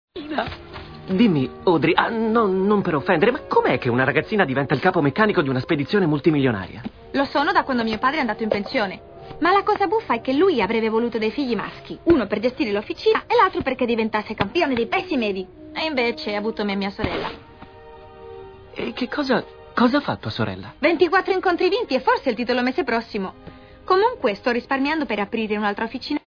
Il mondo dei doppiatori
nel film d'animazione "Atlantis - L'impero perduto", in cui doppia Audrey.